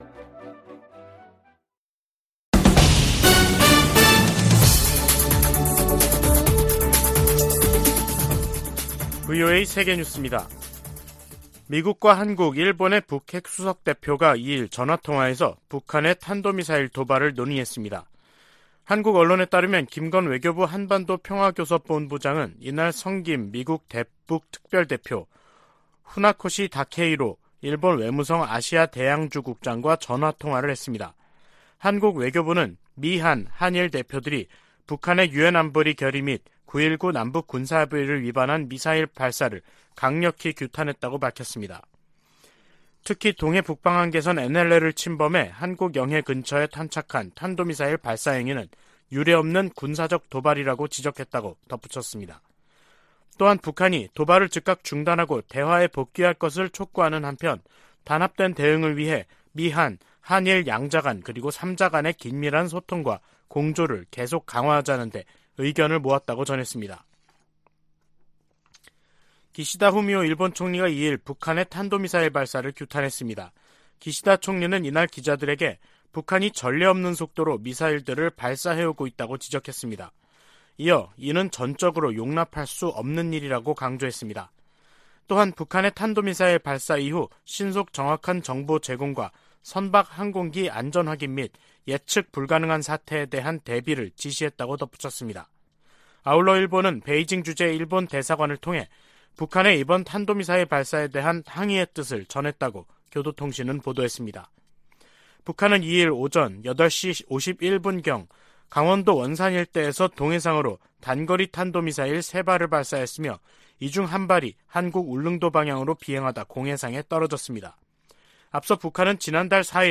VOA 한국어 간판 뉴스 프로그램 '뉴스 투데이', 2022년 11월 2일 3부 방송입니다. 북한 정권이 남북 분단 이후 처음으로 동해 북방한계선 NLL 이남 한국 영해 근처로 탄도미사일을 발사하는 등 미사일 수십 발과 포병 사격 도발을 감행했습니다.